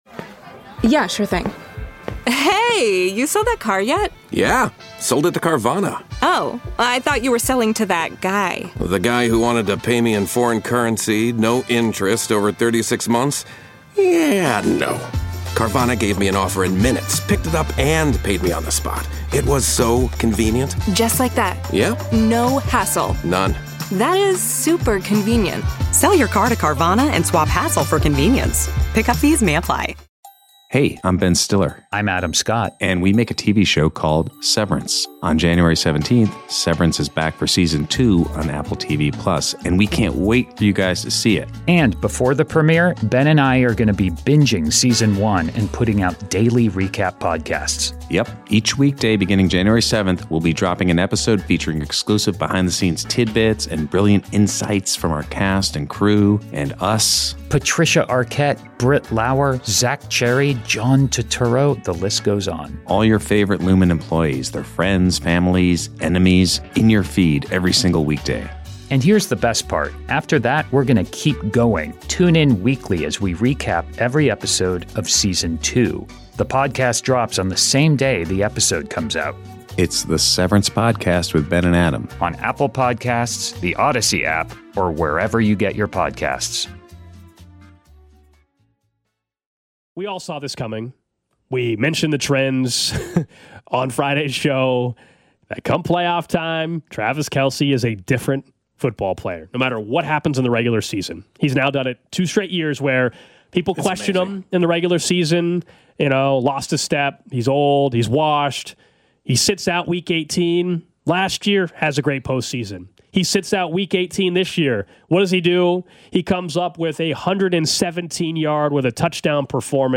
It will also feature our listeners with your calls, texts & tweets as we want you to be a part of the show, not just a listener.